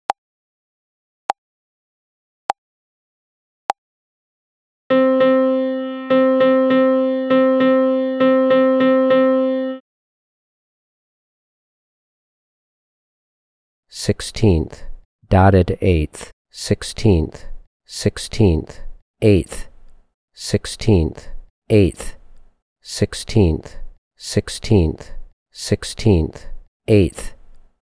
• Level 6: Quarter, Eighth and Sixteenth Note Rhythms in 4/4.
Find examples below for each level of the voice answer MP3s:
Rhy_ET_L6_50BPM-1.mp3